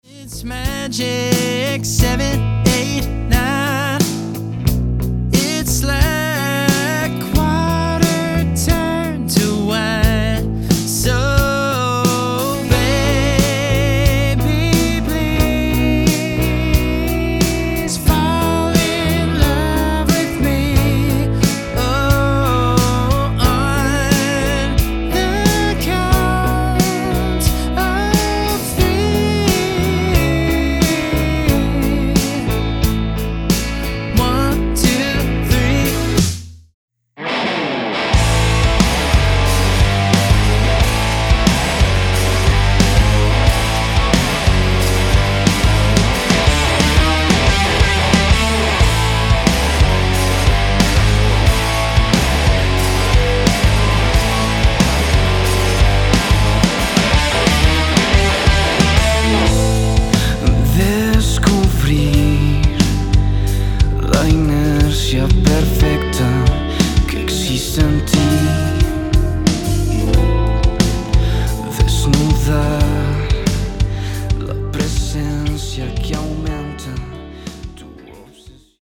Rock_Samples_BYPqjROaxg.mp3